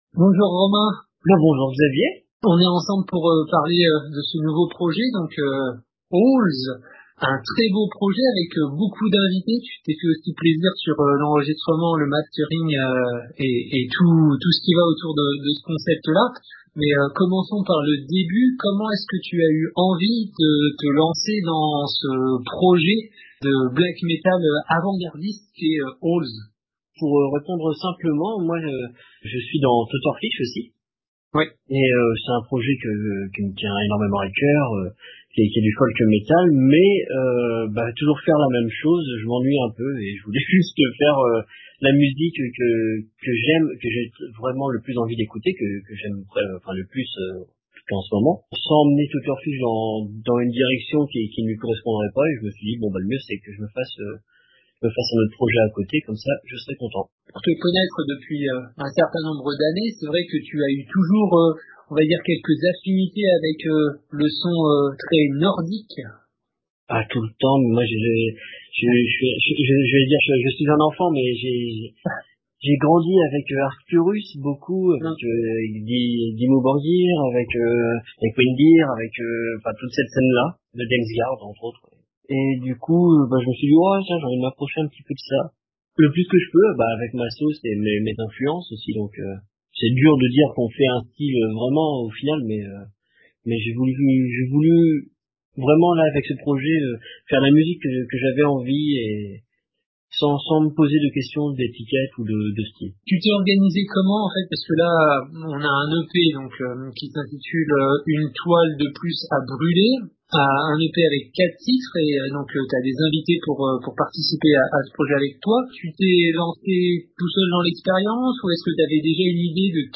Owls - itw - 09.06.2025